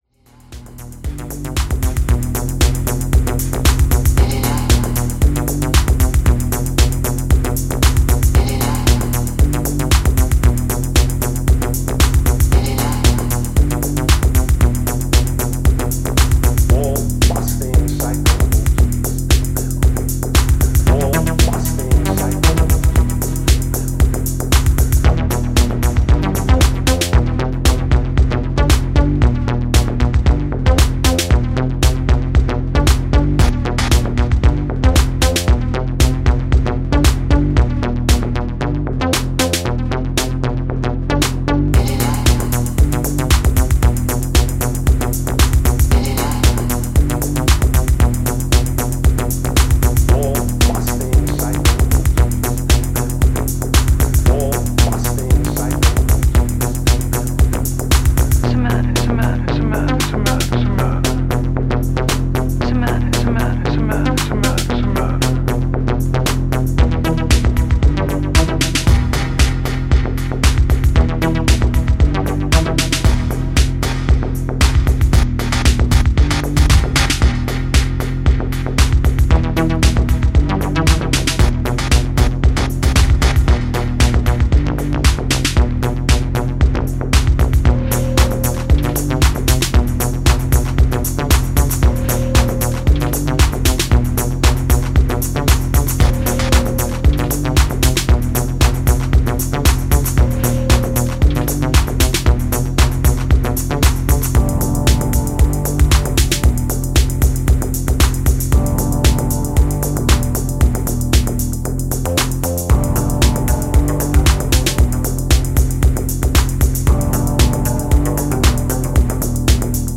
ダーク・コールドウェイヴなスローモーアシッド